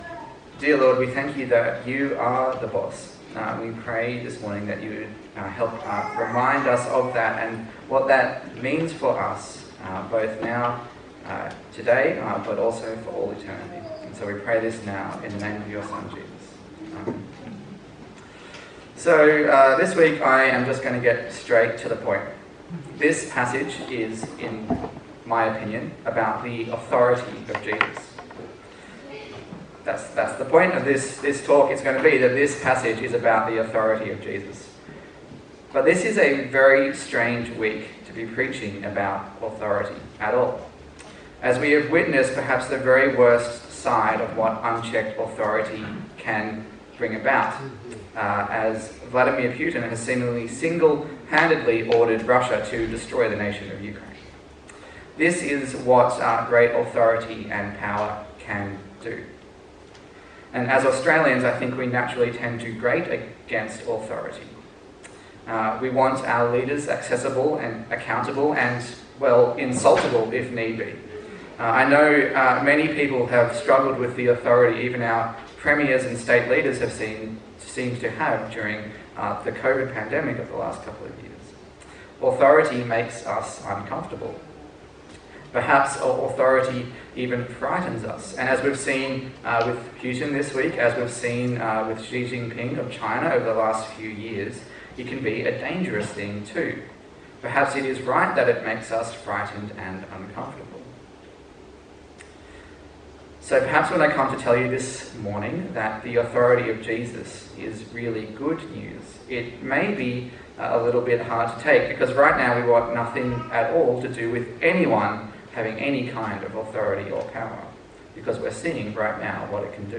Service Type: Sunday Service A sermon in the series on the Gospel of Luke